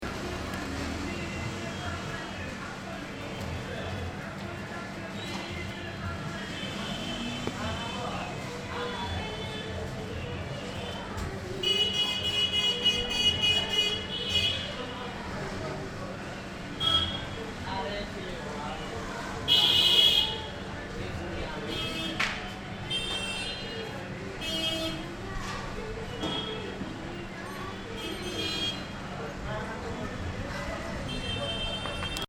ruelle, New Delhi | Prete moi tes yeux